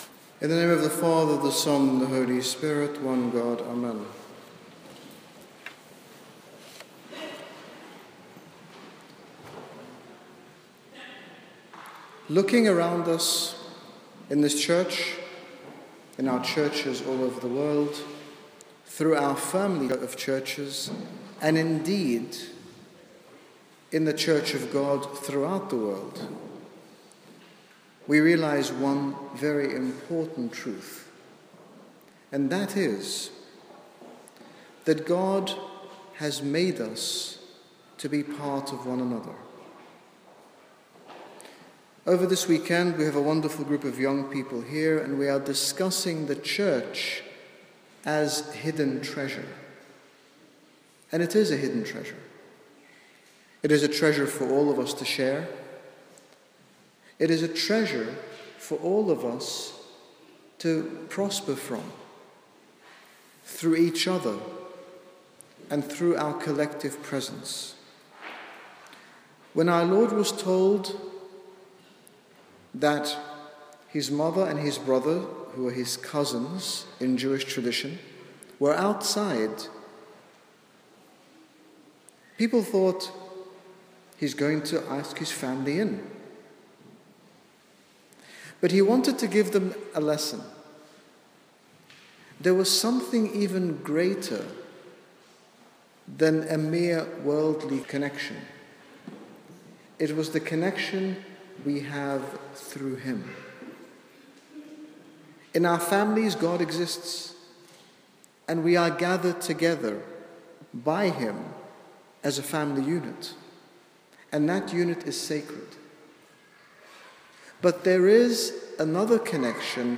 Reclaiming Radical Sermon.mp3